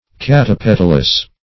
Search Result for " catapetalous" : The Collaborative International Dictionary of English v.0.48: Catapetalous \Cat`a*pet"al*ous\, a. [Pref. cata + petalous.]